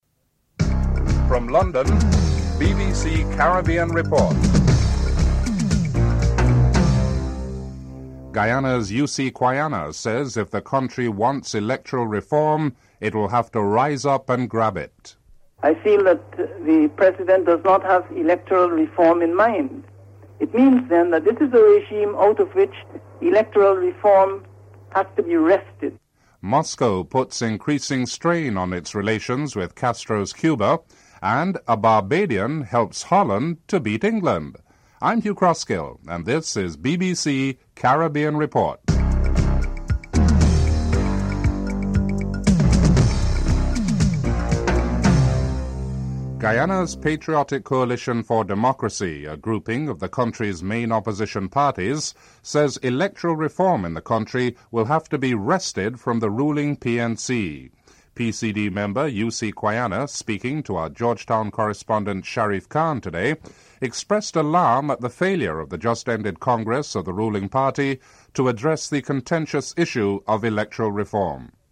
1. Headlines (00:41-01:18)
4. Financial news (07:45-09:40)